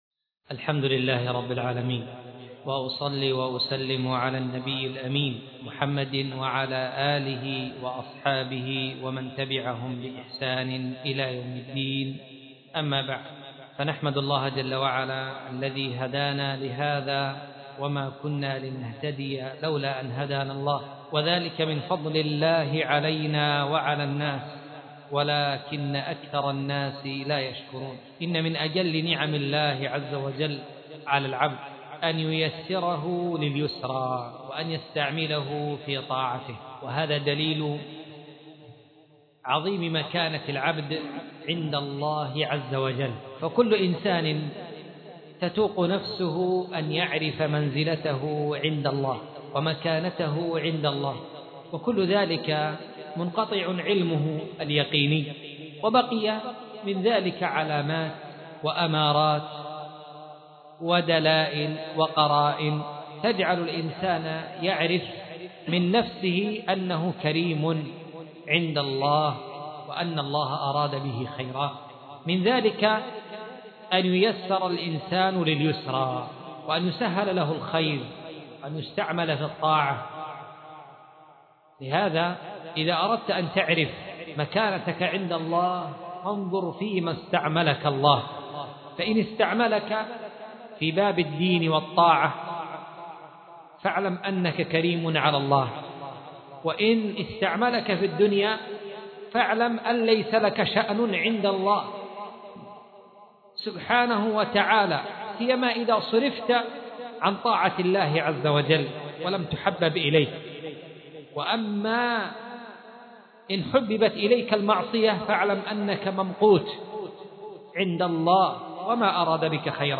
مسجد عائشة / حي البساتين / محافظة عدن حرسها الله